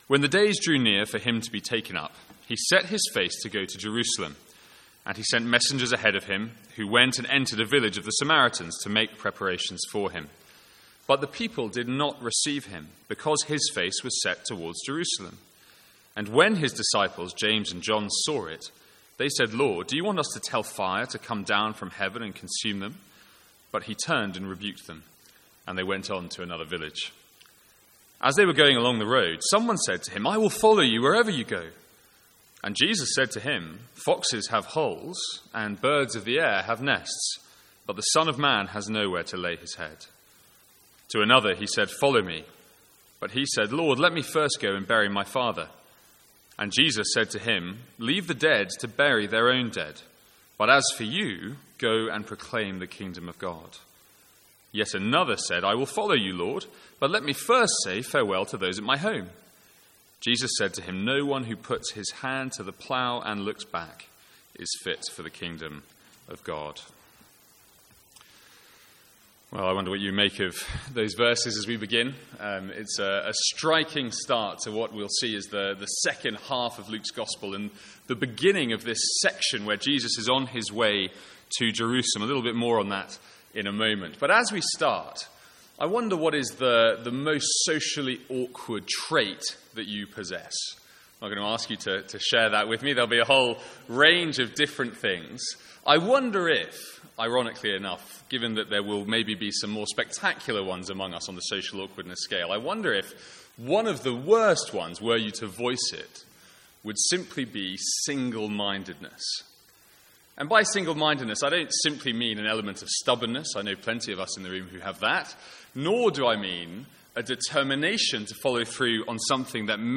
Sermons | St Andrews Free Church
From the Sunday evening series in Luke.